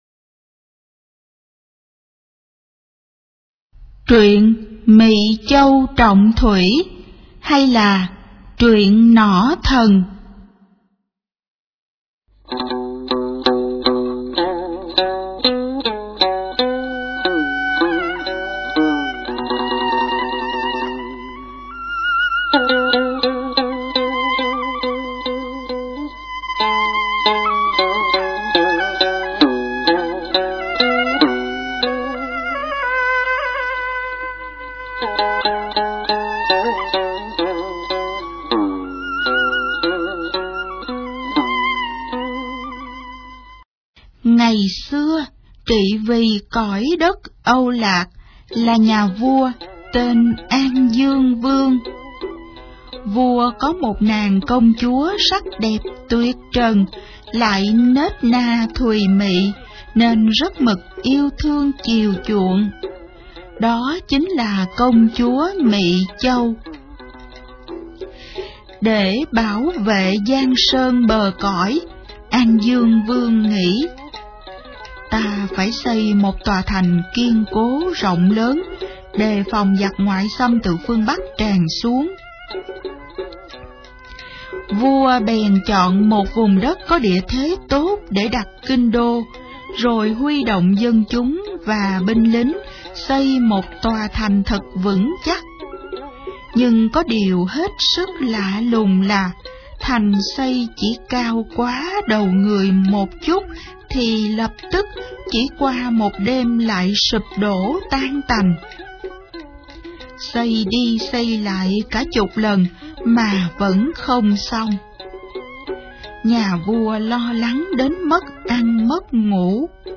Sách nói | Mỵ Châu Trọng Thủy